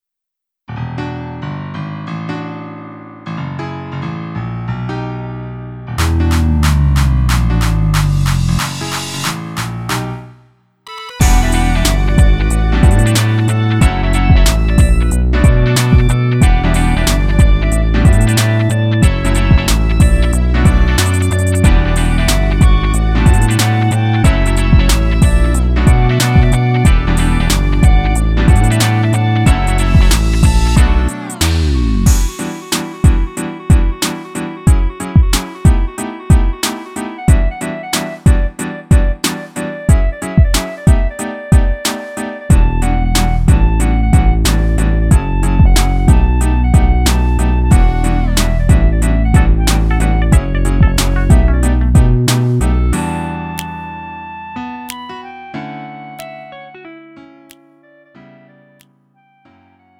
음정 원키 3:44
장르 가요 구분